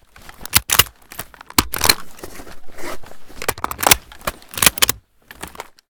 sv98_reload_empty.ogg